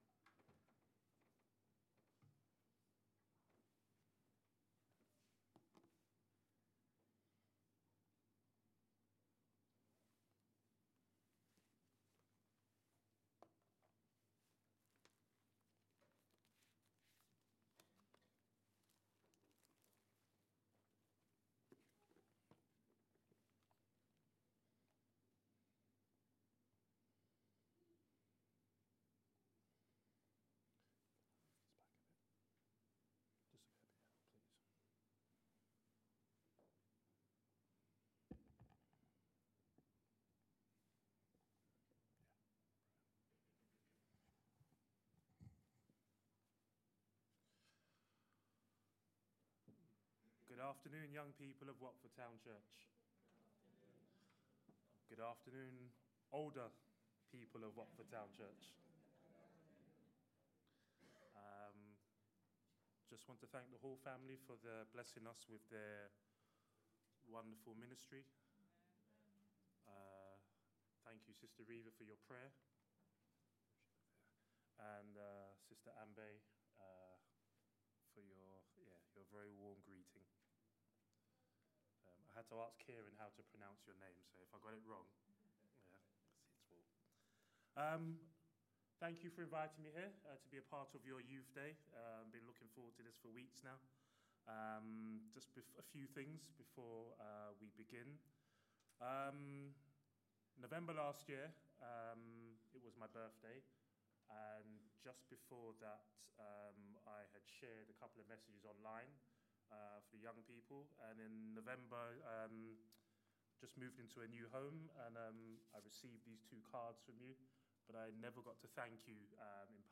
Family Service Recordings